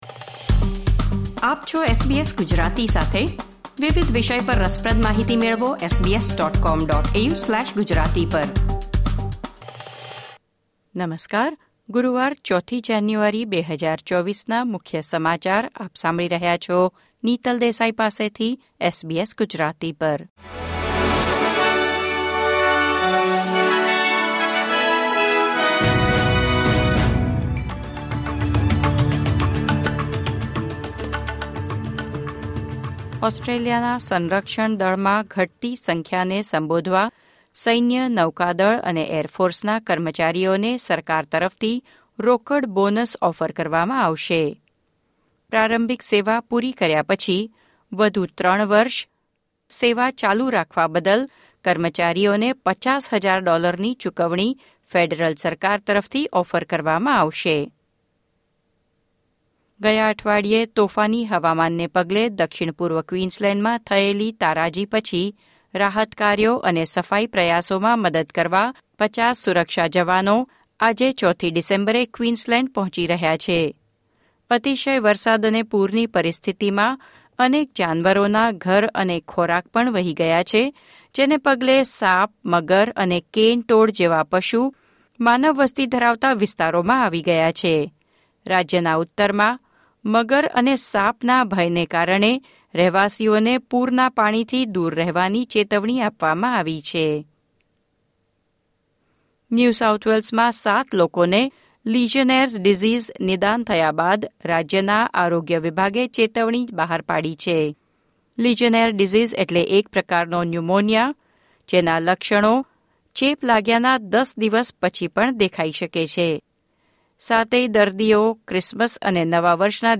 SBS Gujarati News Bulletin 4 January 2024